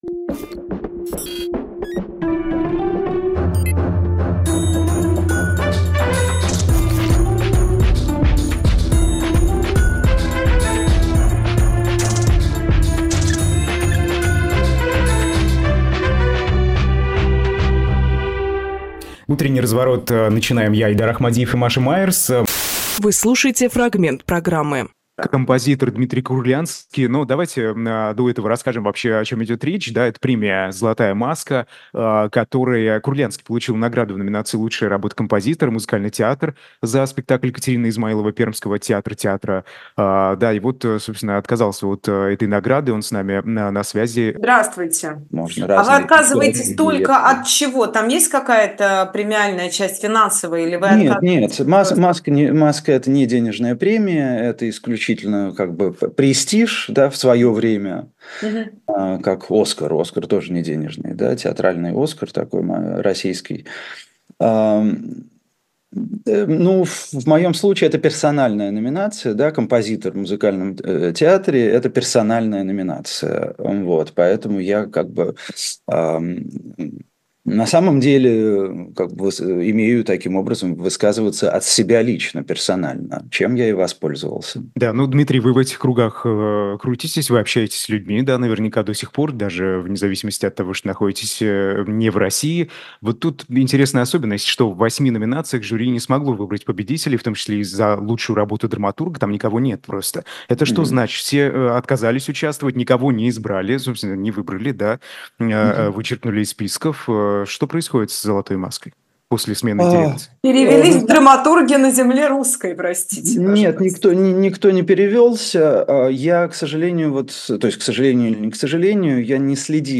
Фрагмент эфира от 27.06.24